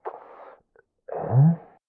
三楼/囚室/肉铺配音偷听效果处理